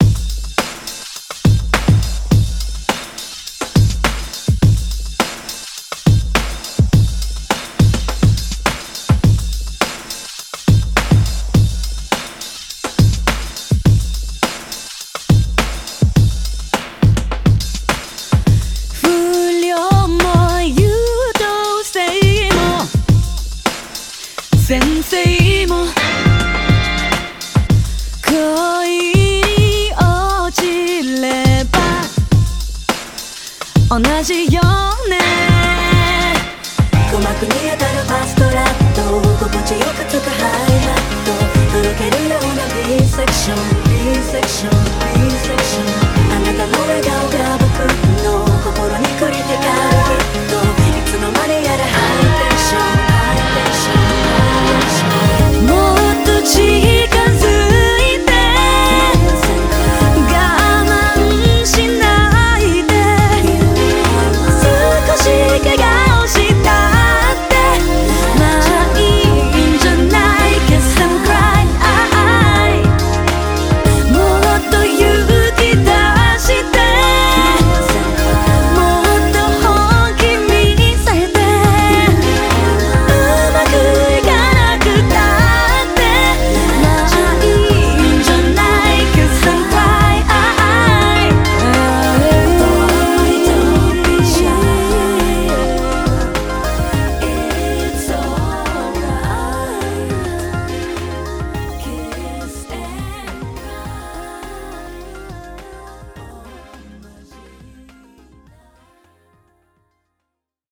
BPM104
Audio QualityPerfect (High Quality)
It's a cool, chill R&B track with a killer beat.